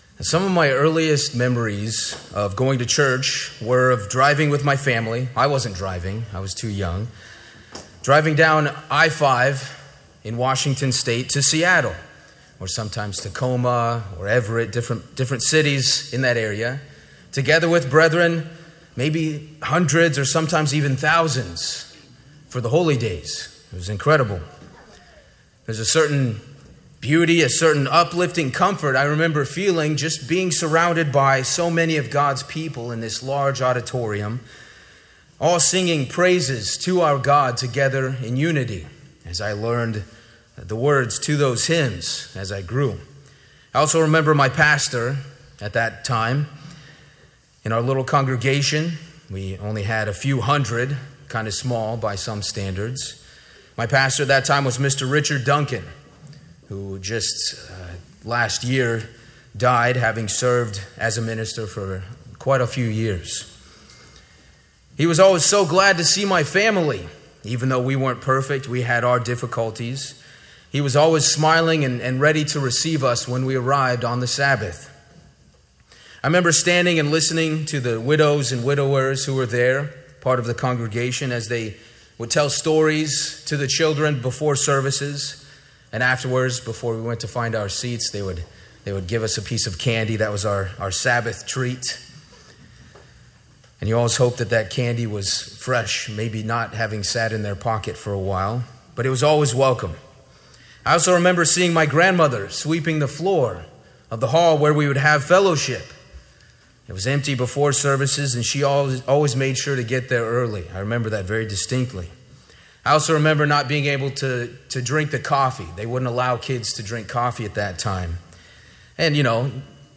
Sermons
Given in New York City, NY New Jersey - North